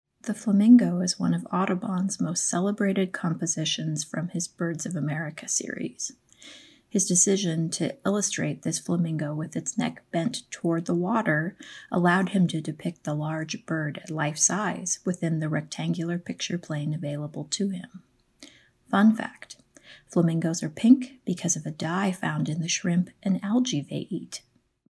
Listen to a curator talk about this work.